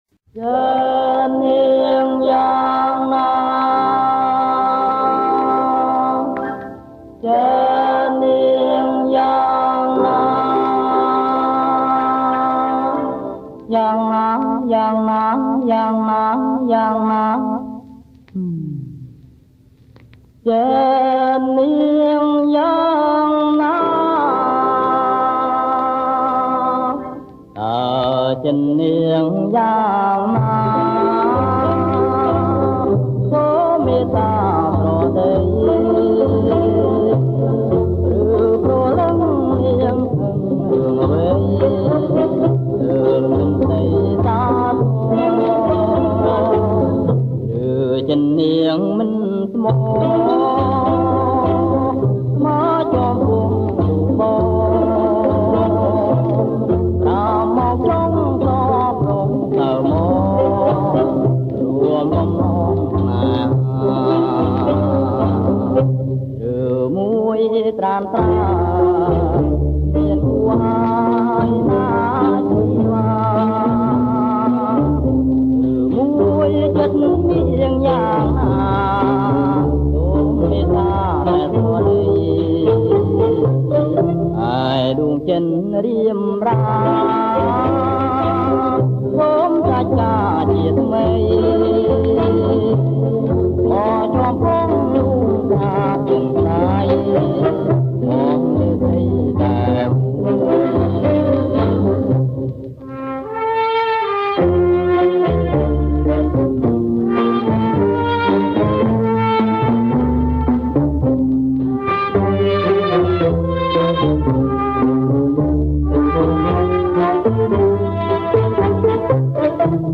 • ប្រគំជាចង្វាក់ Bolero Twist
ប្រគំជាចង្វាក់  Bolero Twist